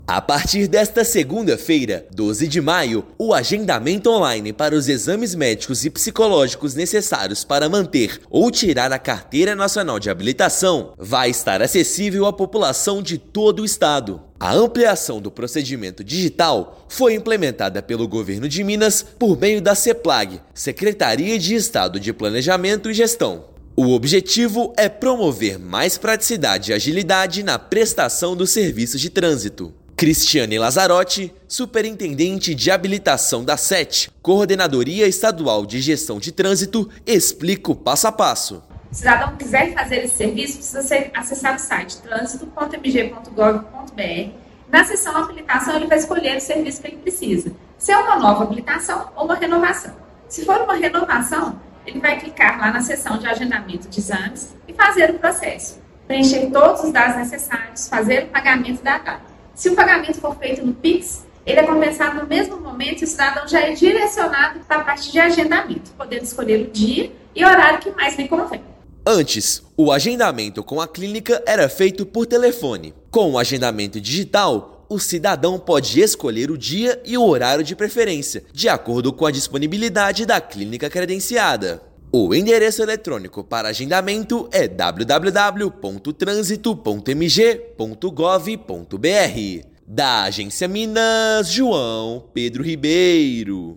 Cidadão pode escolher dia e horário de preferência, de acordo com a disponibilidade da clínica credenciada. Ouça matéria de rádio.